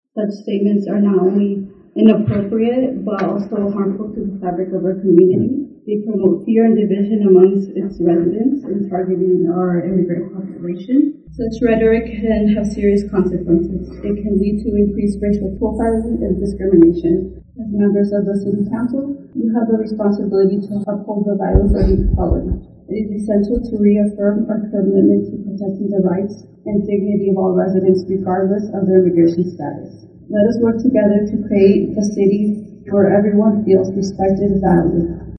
Monday night, during public comment of the Hampton City Council budget workshop meeting, some residents voiced their concerns about councilmember Bill Hodge and a remark he made during the February 3rd meeting about ICE making arrests in the area.